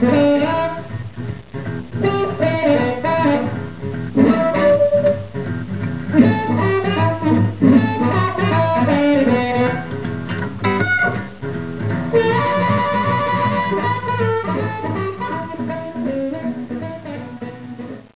gbaharp.wav